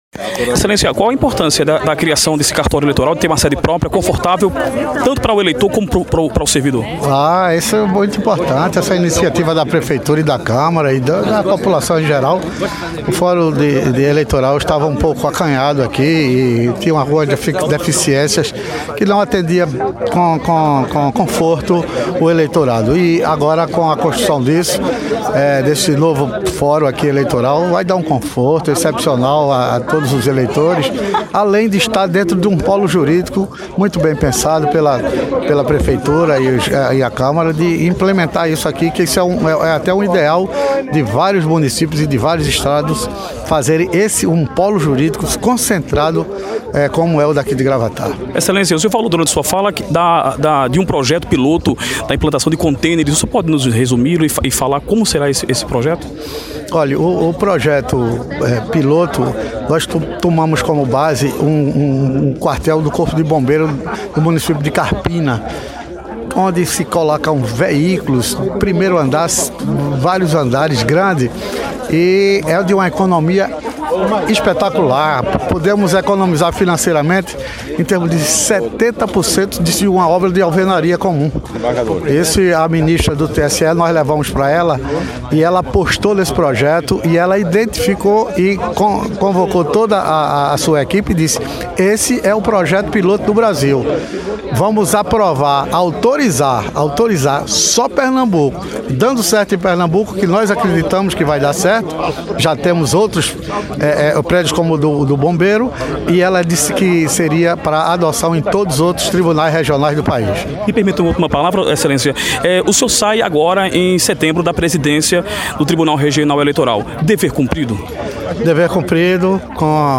Em entrevista para o site, o desembargador presidente do TRE/PE falou da importância da obra para a Justiça Eleitoral, em Gravatá. Ouça a entrevista: